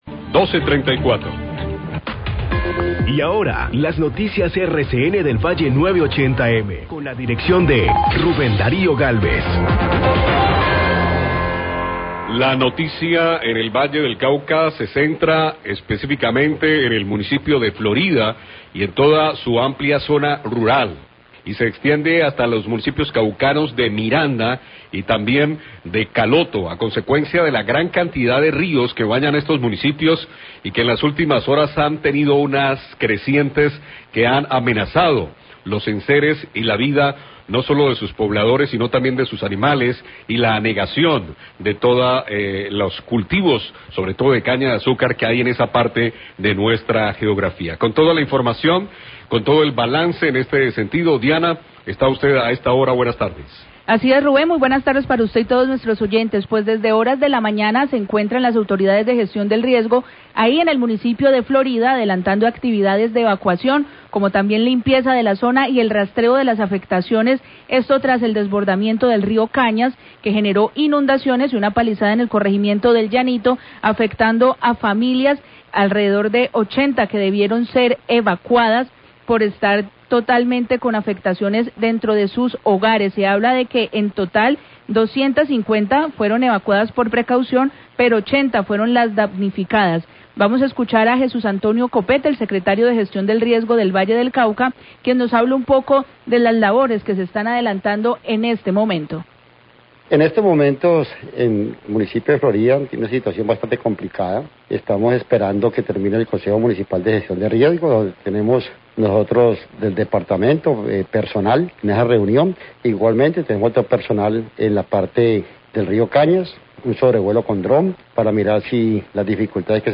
Radio
El Secretario de Gestión del Riesgo del Valle, Jesús Copete, presenta un balance de las afectaciones al municipio de Florida por la creciente del Río Cañas. Periodista dice que en este municipio se encuentran también cultivos de caña que pudieron verse afectados.